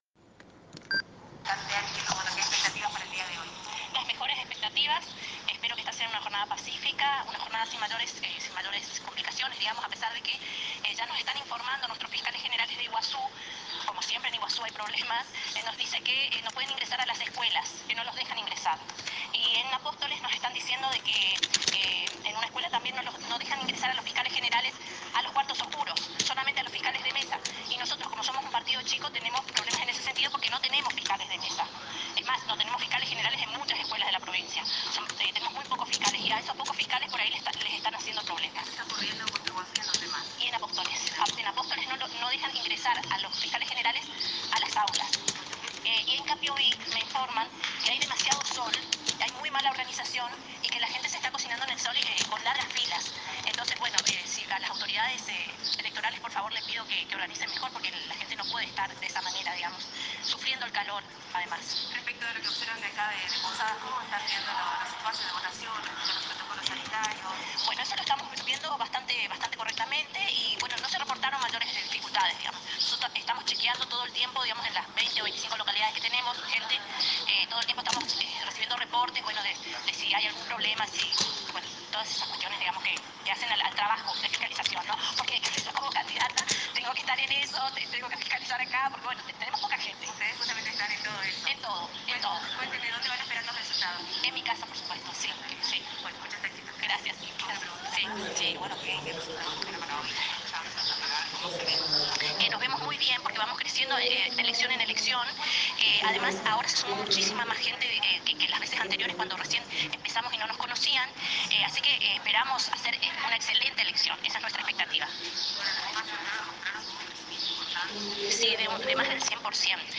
Con fuerte tono crítico